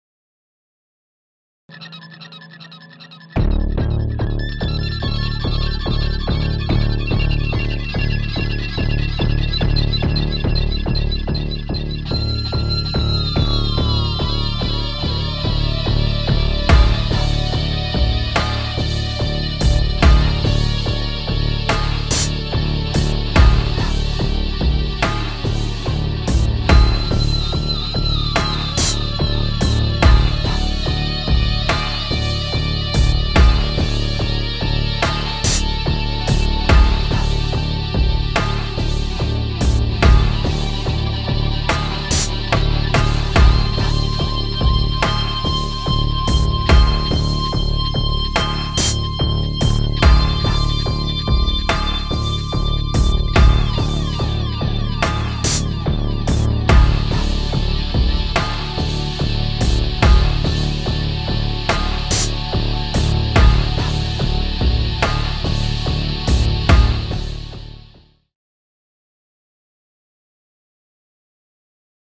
Industriel